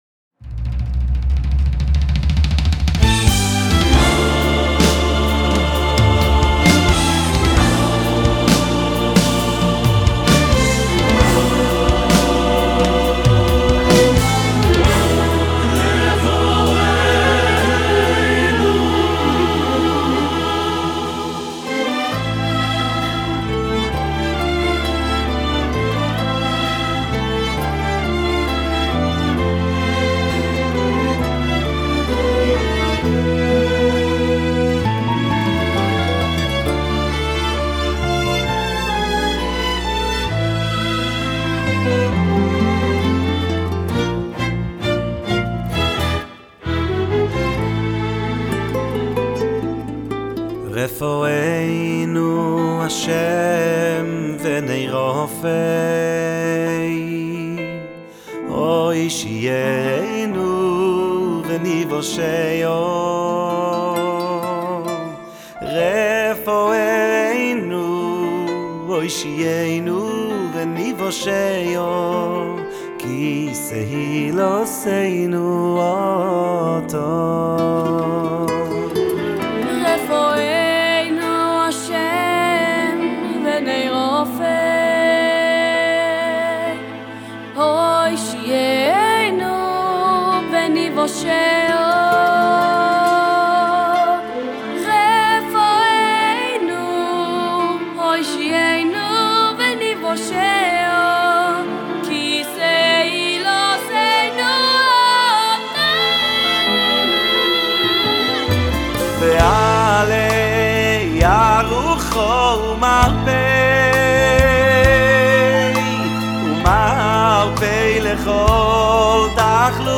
שמרגש בקולו הצלול
עיבוד מלטף ונוגע ללב